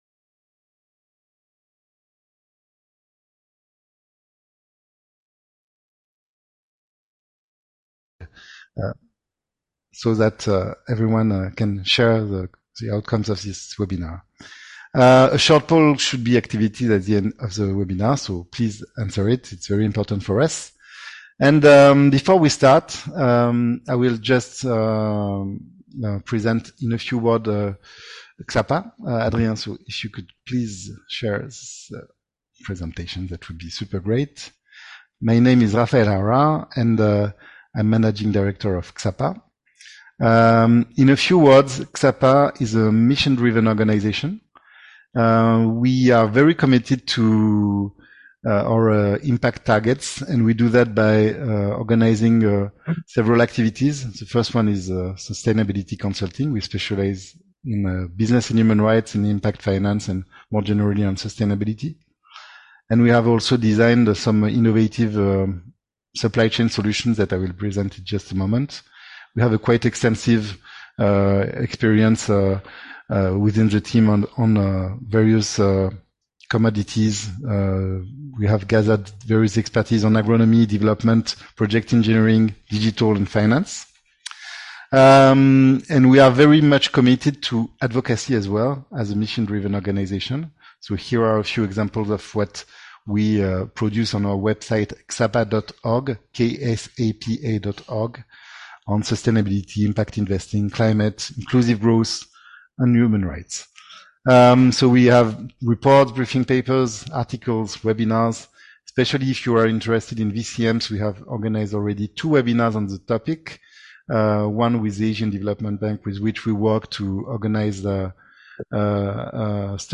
Webinar: How to ensure socially & environmentally impactful Voluntary Carbon Markets